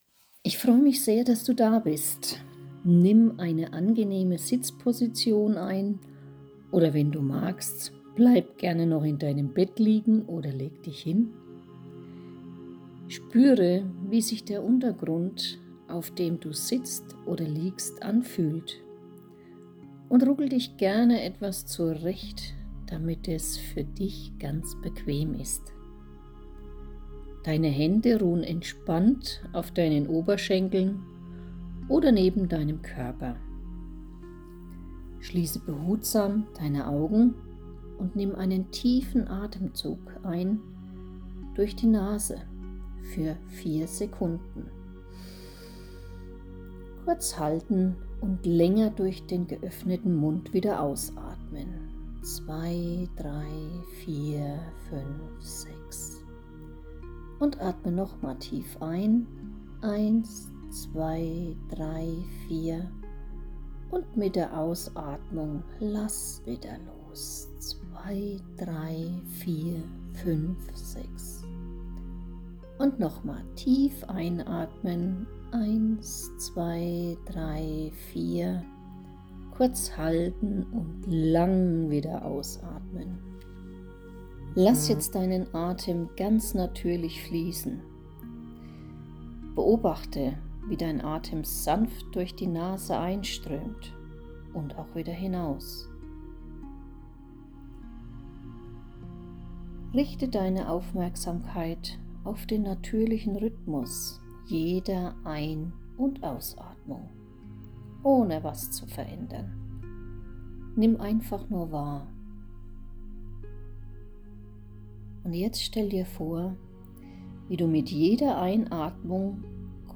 Meditation_Selbstvertrauen.mp3